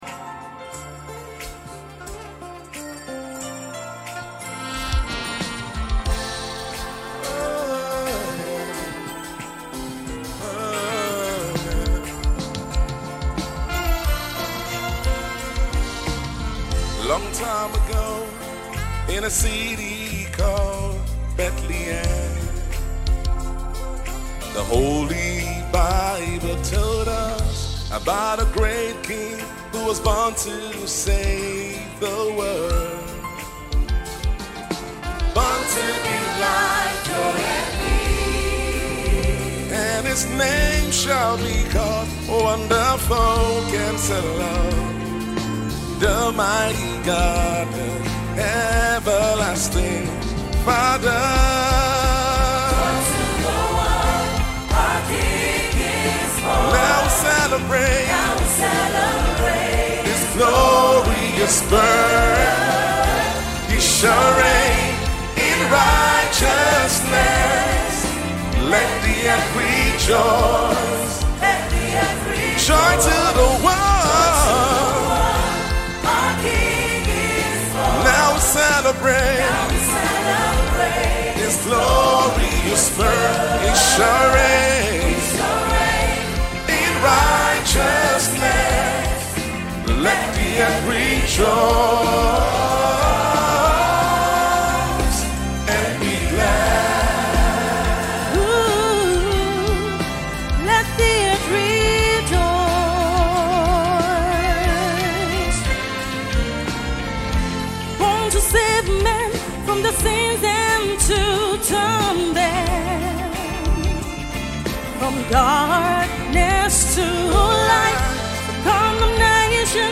MORE CAROL SONGS